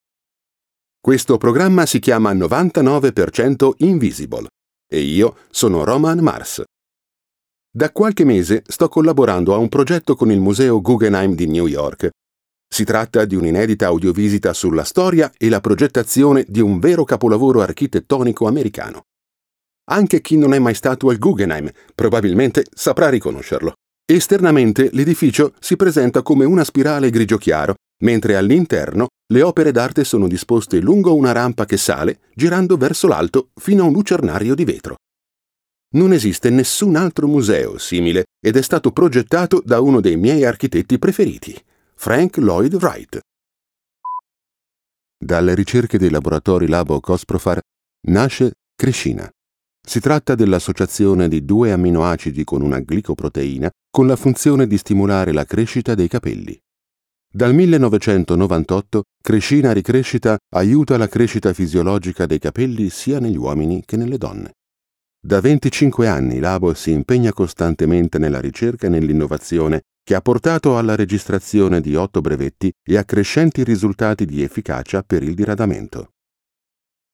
I am a native Italian voice actor talent for documentaries, e-learning, infomercials, audio-guides, media content, advertisements and audio book narrations
Kein Dialekt
Sprechprobe: eLearning (Muttersprache):